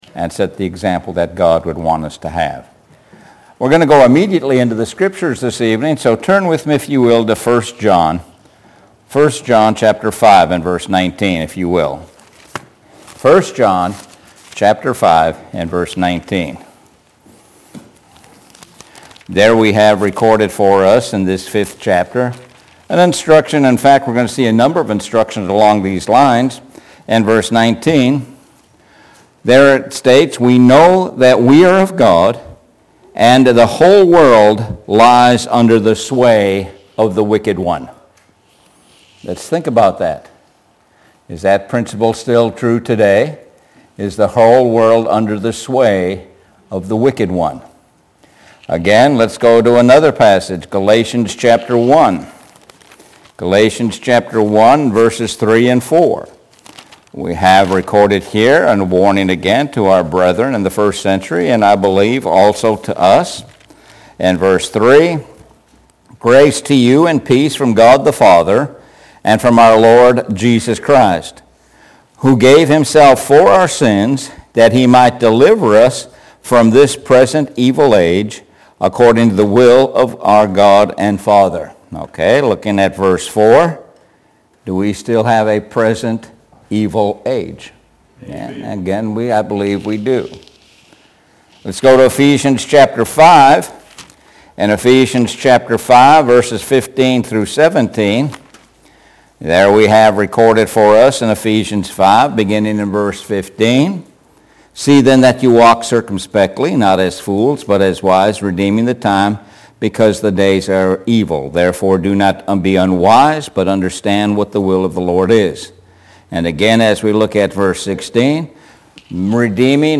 Sun PM Sermon – Courage and conflict